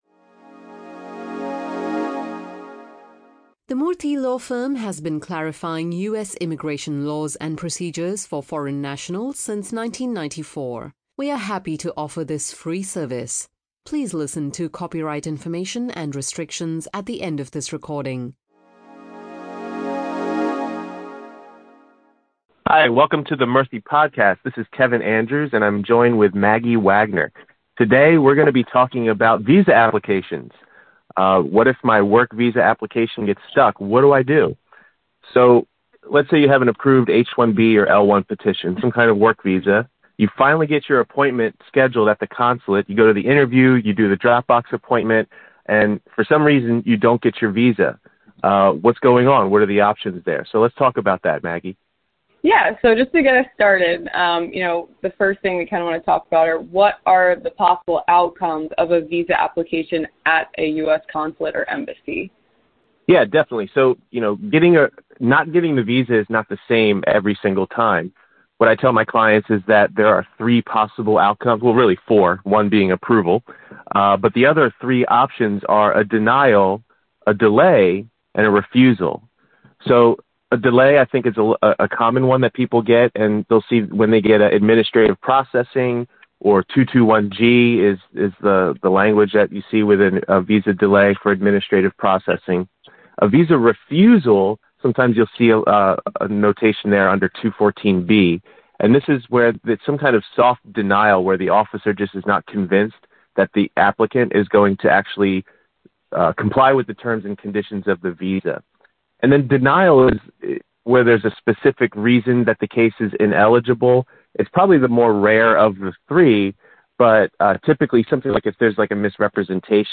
Common visa stamping issues and strategies involved in consular processing are the topic of this Q/A session recorded for you by Murthy Law Firm attorneys.